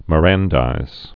(mə-răndīz)